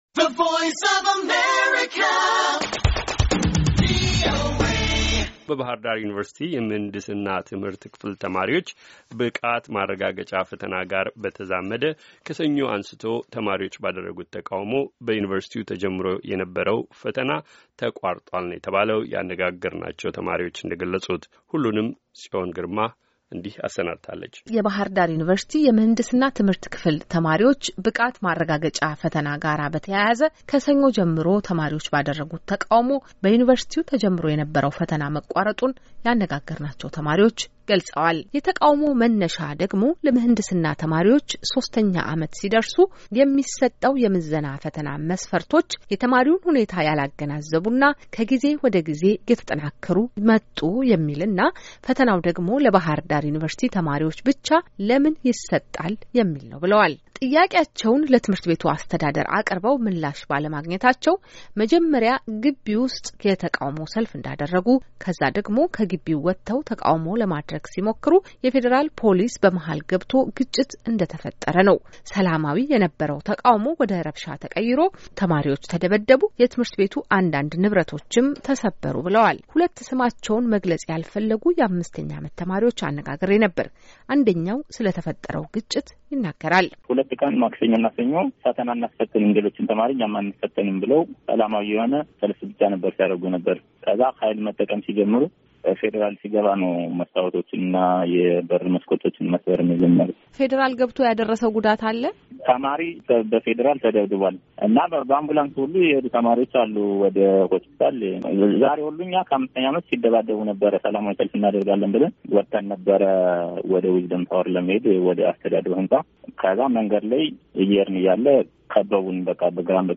በባህርዳር ዩኒቨርስቲ የምሕንድስና ትምሕርት ክፍል ተማሪዎች ብቃት ማረጋገጫ ፈተና ጋራ በተያያዘ ተማሪዎች ባደረጉት ተቃውሞ በዩኒቨርስቲው ተጀምሮ የነበረው ፈተና መቋረጡን ያነጋገርናቸው ተማሪዎች ገለጹ።